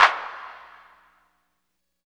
88 FT CLAP-L.wav